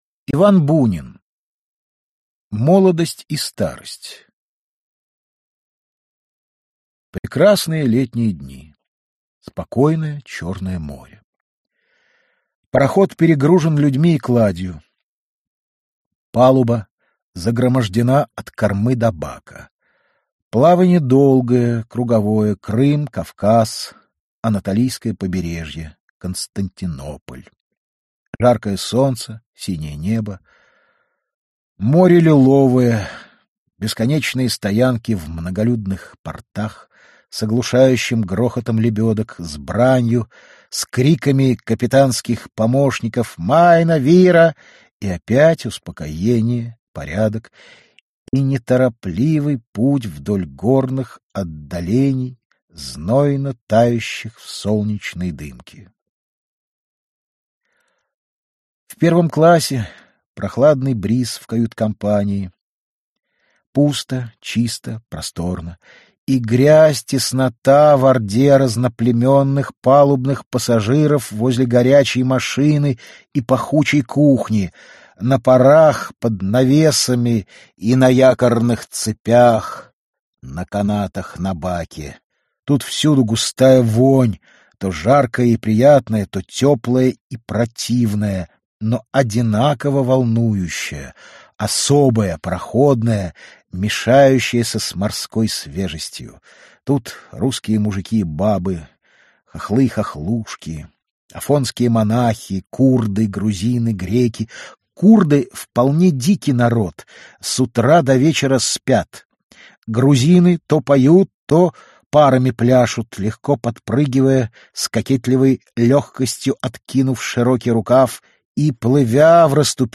Аудиокнига Домой с небес (сборник) | Библиотека аудиокниг
Aудиокнига Домой с небес (сборник) Автор Коллектив авторов Читает аудиокнигу Актерский коллектив.